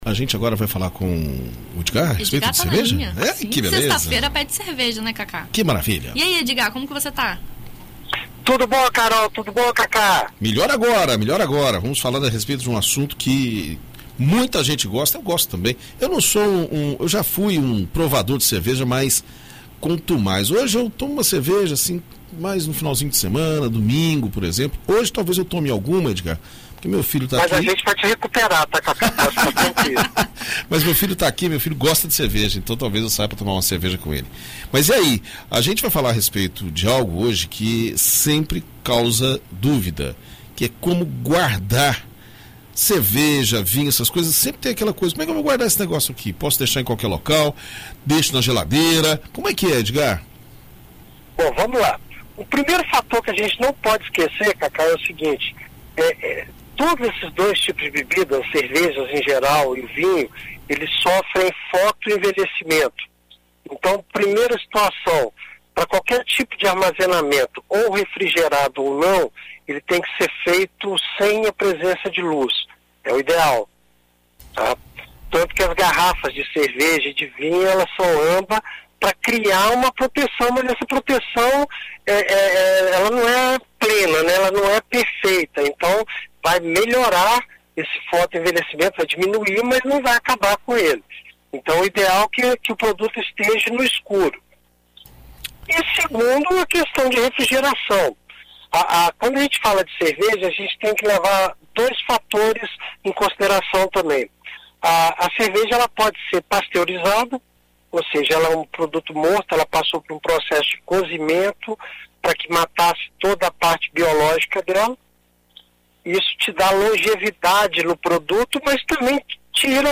Em entrevista à BandNews FM ES nesta sexta-feira (13)